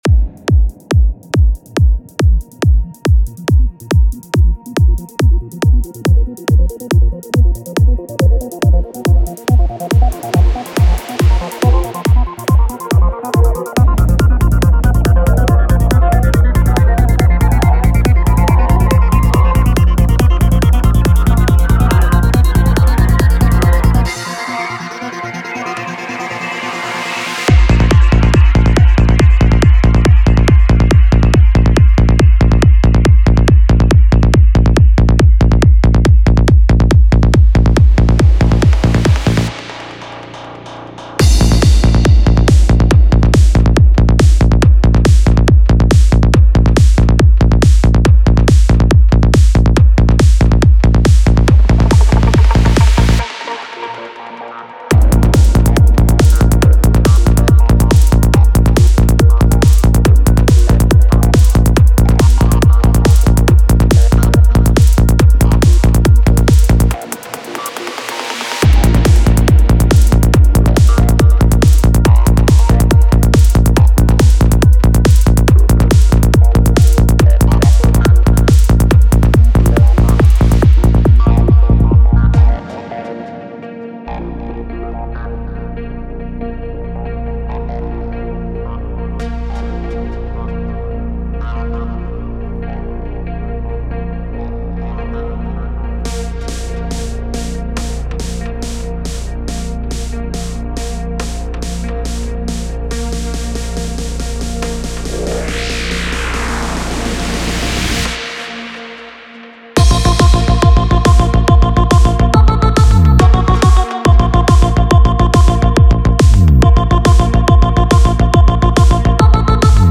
Psy-Trance Trance
Key & BPM 140 - G#m